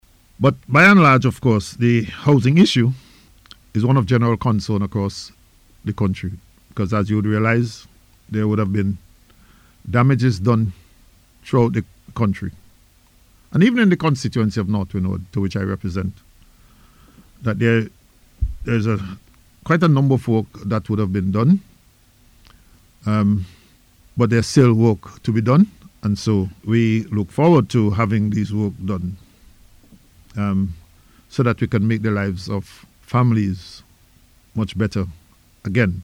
Speaking recently on NBC Radio, Minister Daniel assured that the government is committed to completing the work needed to improve living conditions for affected families.